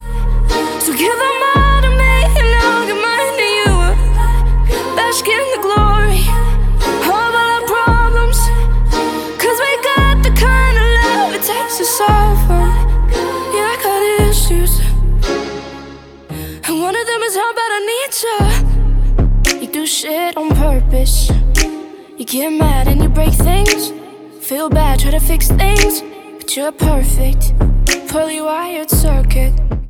• Pop
it is a confessional downtempo pop song.